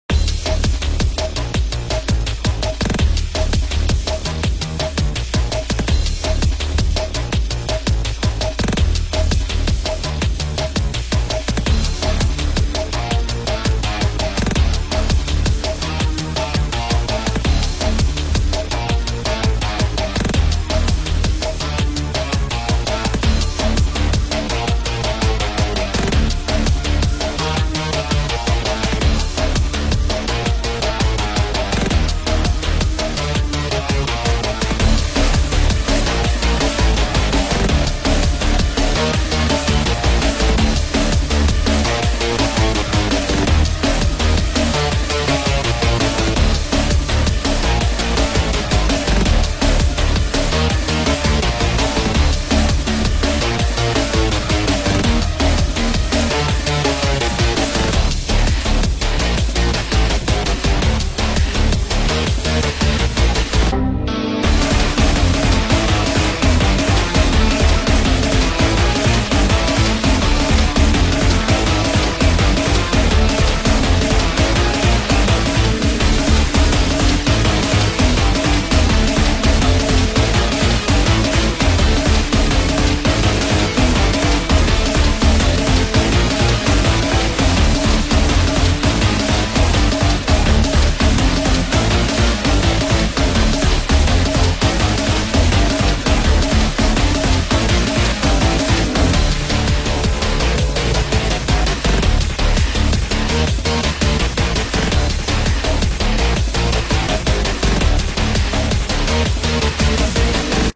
(без слов)